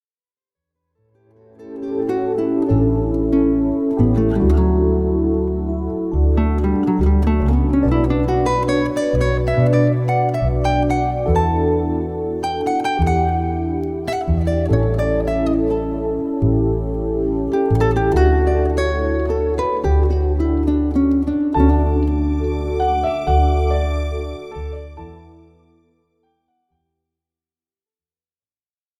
Version instrumentale